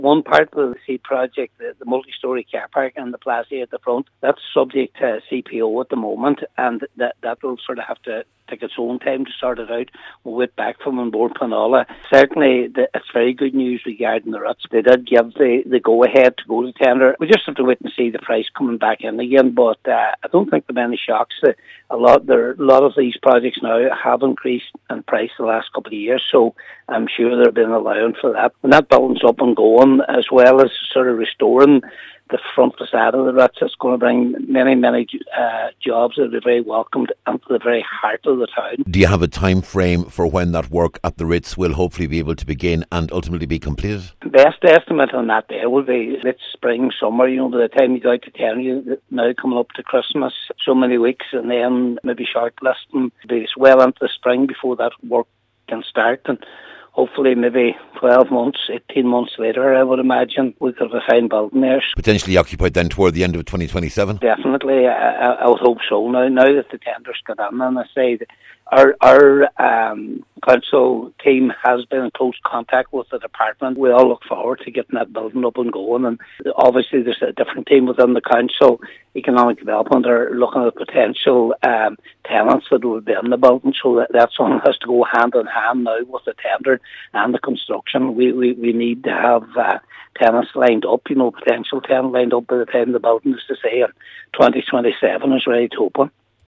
Cllr McGowan says the progress with the Ritz building is significant…………….